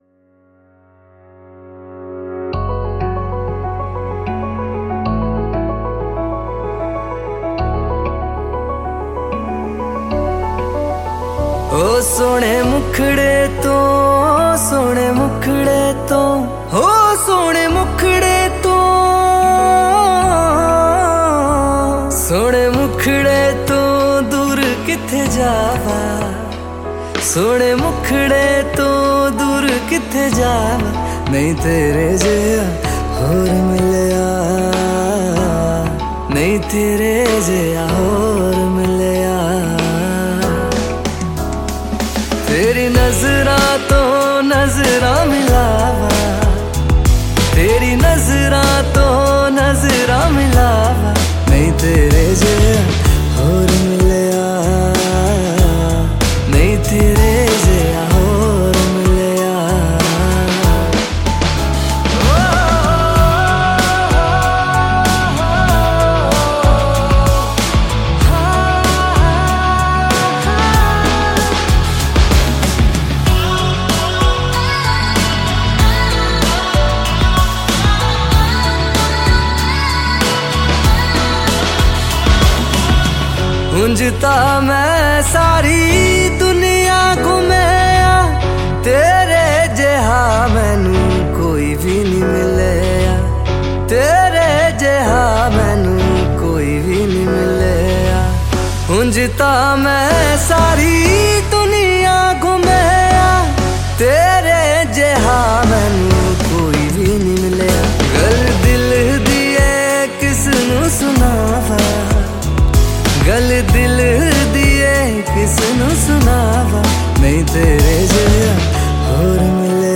New Version Cover